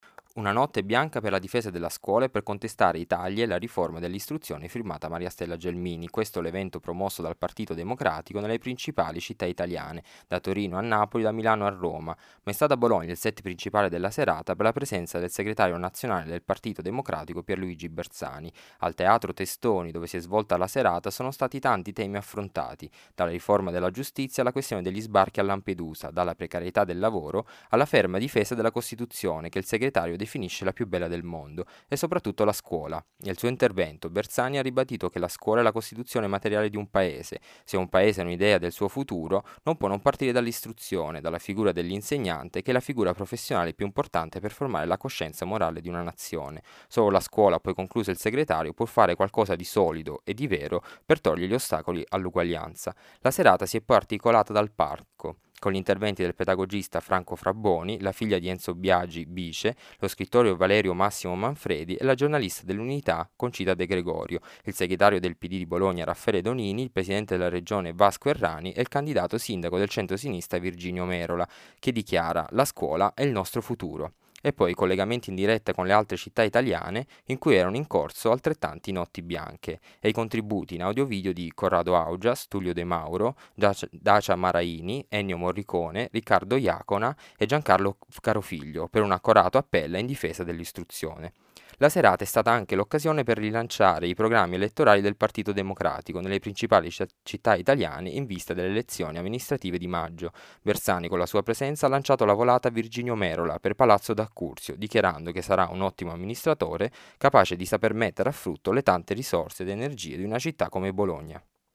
A Bologna, al Teatro Testoni, a partire dalle 18 sono sfilati molti personaggi: il pedagogista Franco Frabboni, la figlia di Enzo Biagi, Bice, lo scrittore Valerio Massimo Manfredi e la direttrice dell’Unità Concita De Gregorio. oltre mille persone hanno poi affollato la sala per ascoltare il segretario nazionale del Pd, Pier Luigi Bersani e il candidato sindaco del centro-sinistra Virginio Merola. molte le  testimonianze e  i contributi video dtra cui quelli di Corrado Augias, Tullio De Mauro, Dacia Maraini, Ennio Morricone, Riccardo Iacona e Giancarlo Carofiglio.
il servizio